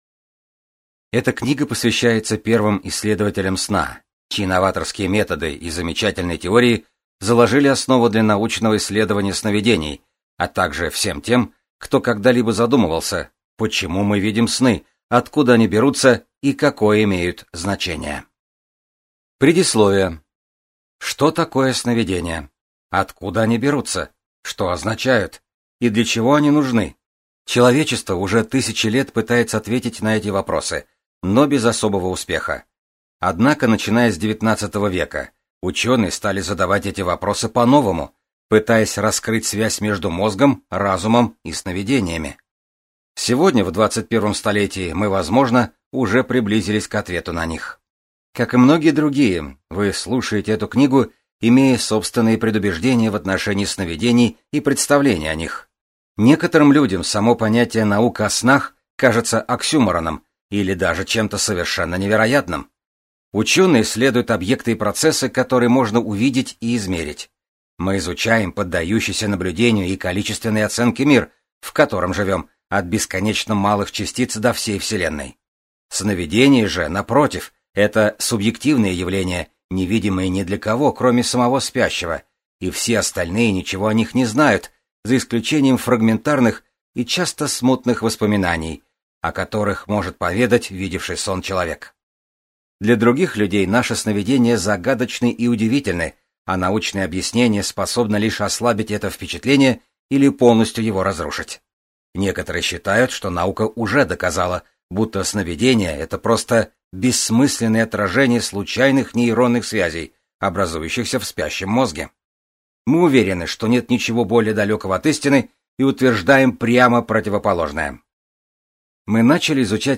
Аудиокнига Когда мозг спит: Сновидения с точки зрения науки | Библиотека аудиокниг